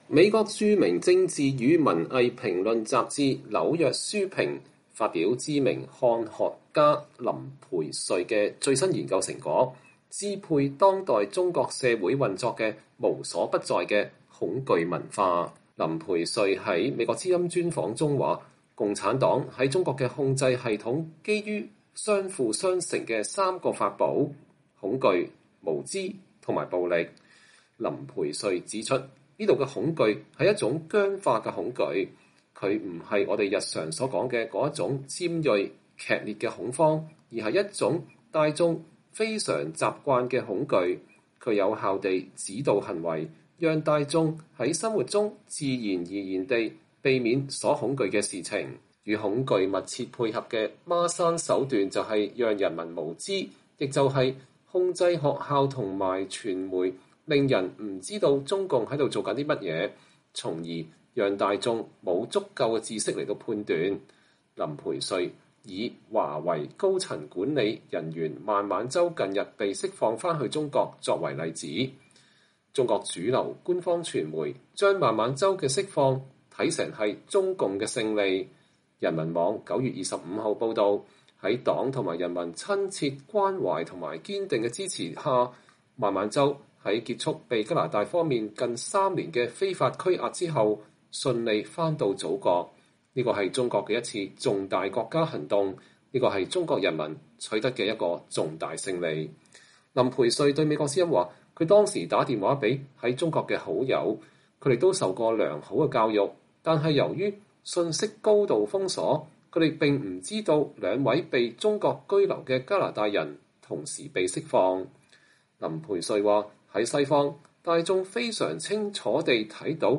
專訪林培瑞：中共賴以治國的“恐懼文化”